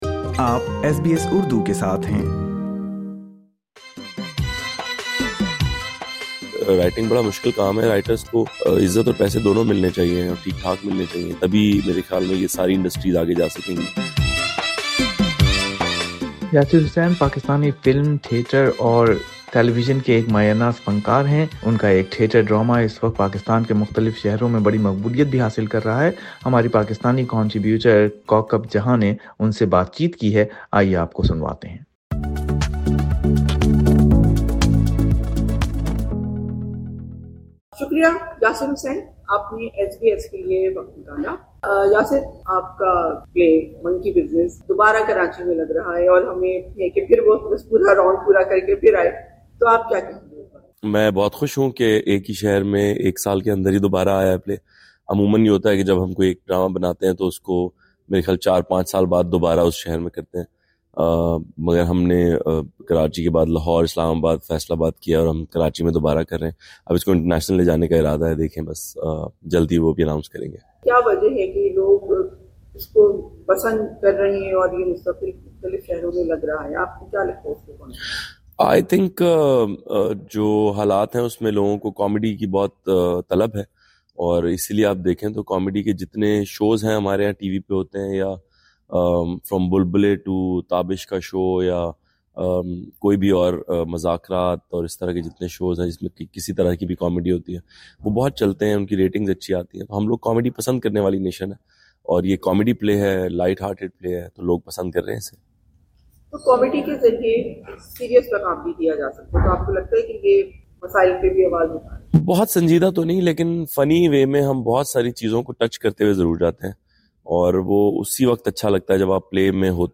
ایس بی ایس کے ساتھ خصوصی گفتگو میں یاسر حسین نے اپنے فن اور پاکستانی انٹرٹینمنٹ انڈسٹری کی مجموعی صورتحال پر بات کی۔